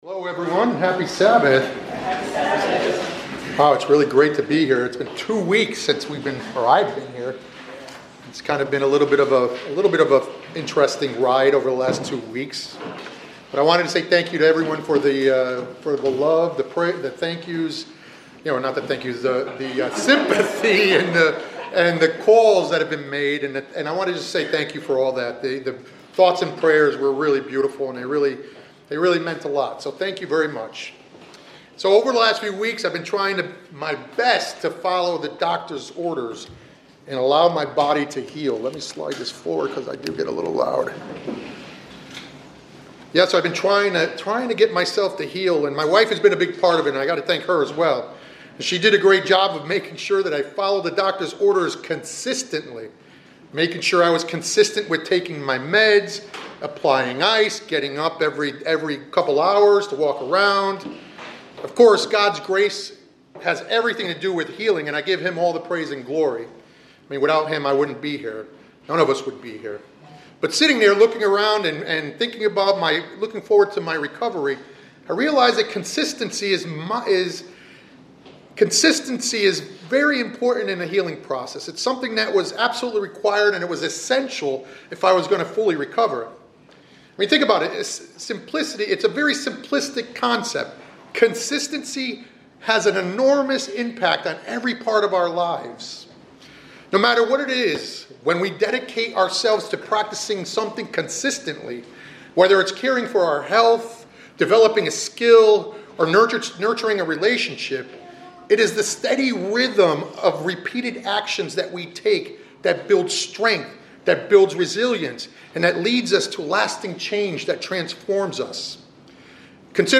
This sermonette emphasizes the transformative power of consistency in spiritual life, highlighting findings from the “Power of Four” study by the Center for Bible Engagement. The message explains that engaging with the Bible at least four times a week leads to significant spiritual growth, improved relationships, and greater empowerment for service in God’s Kingdom.